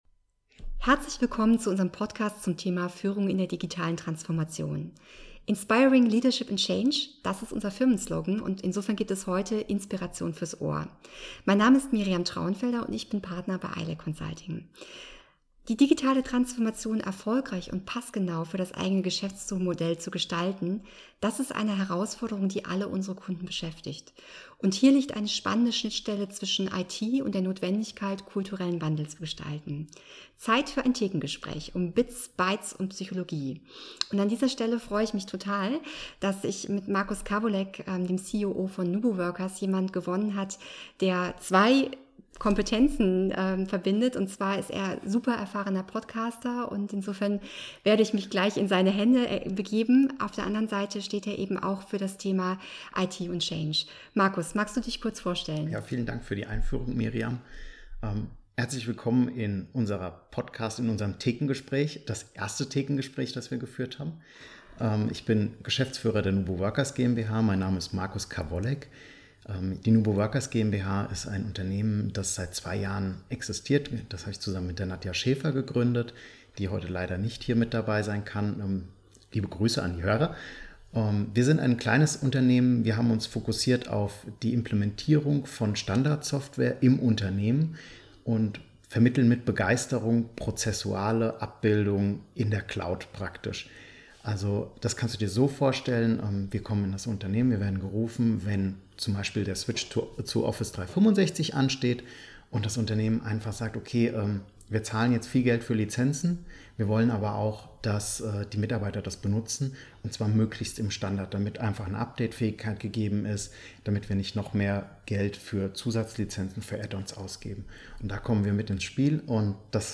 Reingehört - beim Thekengespräch ‚Führung in der digitalen Transformation‘